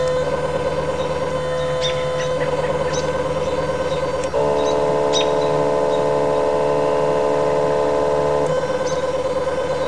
Click for the sound of dual axis microstepping with guiding adjustments (213k WAV)
(You can hear that the robins in the trees make more noise than the scope drive)